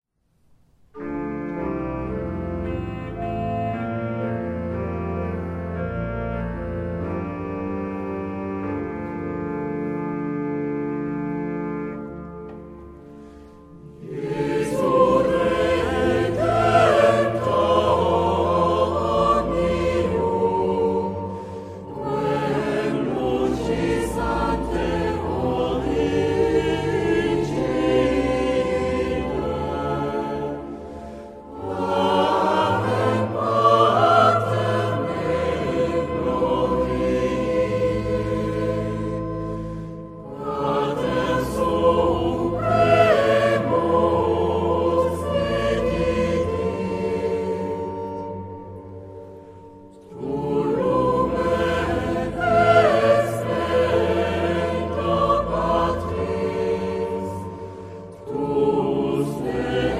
Género/Estilo/Forma: Sagrado ; Himno (sagrado)
Carácter de la pieza : energico
Tipo de formación coral: SATB  (4 voces Coro mixto )
Instrumentos: Organo (1)
Tonalidad : dorico